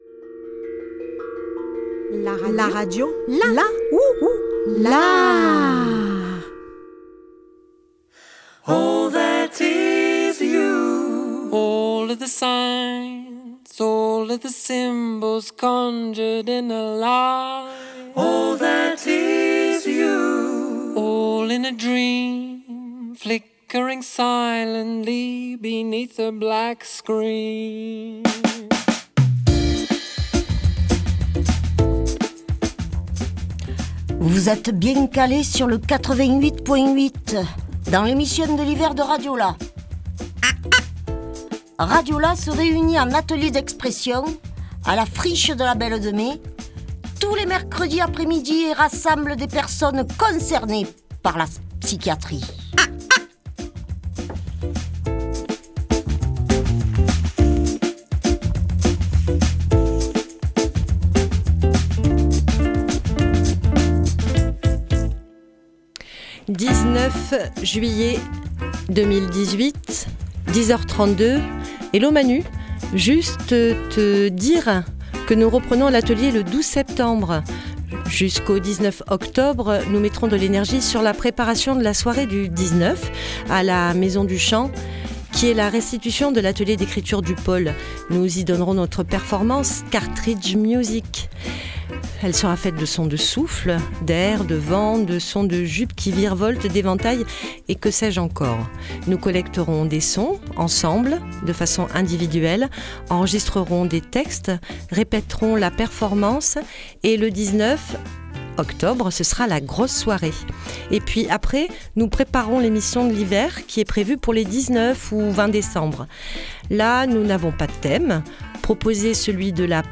Cette émission de l’hiver, elle est en direct, dans les studios de Radio Grenouille, le 20 décembre 2018.